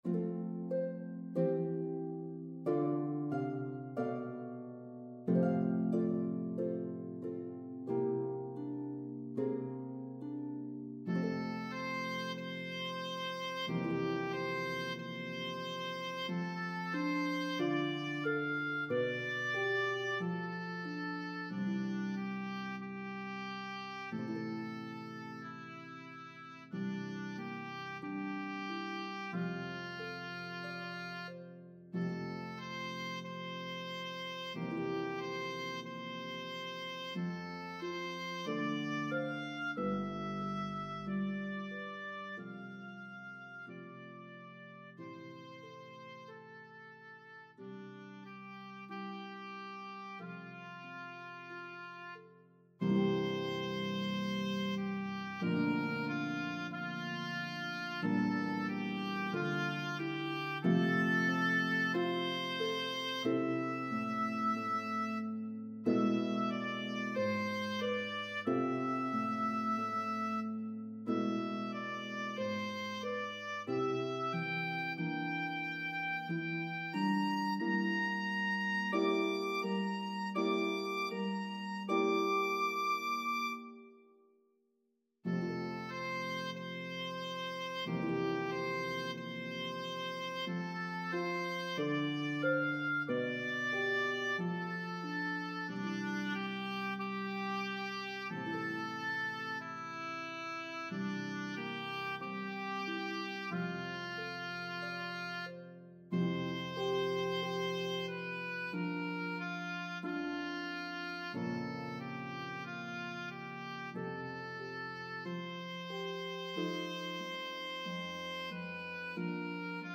The warmth of this duet will enchant your audience.